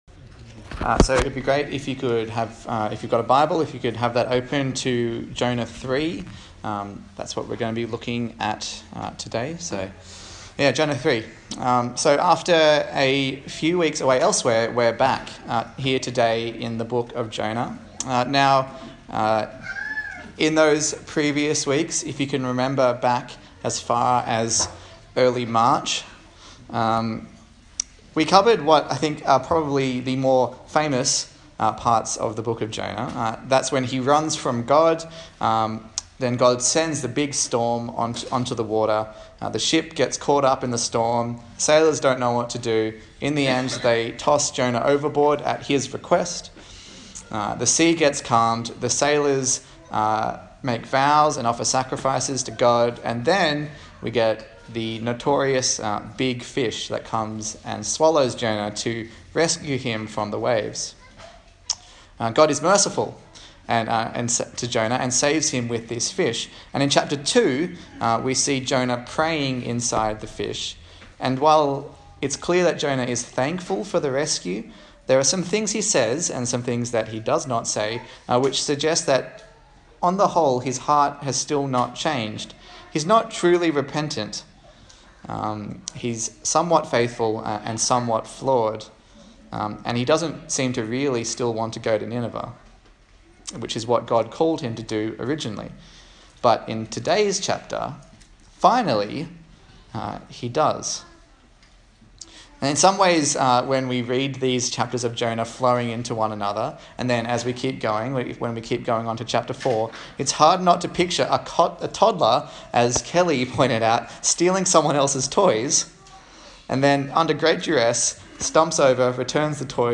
Jonah Passage: Jonah 3 Service Type: Sunday Morning